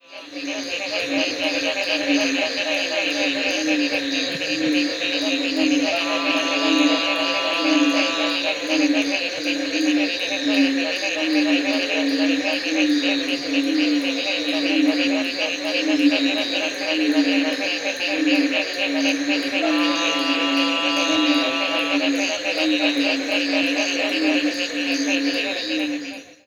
Veined Treefrog
It had rained very hard in this area earlier in the day and lots of frog species were calling.   I pointed my microphone out into the marshes and recorded many species, including a distant chorus of Veined Treefrogs.  I have brought out the Veined Treefrogs in this recording and reduced the gain for all the other species.
The call of this species is a deep "roon".   It is remniscent of the call of the American Bullfrog and a large chorus of these Veined Treefrogs does sound a bit like that species.
The other species heard in this recording include Stauffer's Treefrog, Mexican Treefrog and the Sheep Frog.
Veined Treefrogs, et al. from Campeche, Mexico